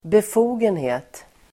Uttal: [bef'o:genhe:t]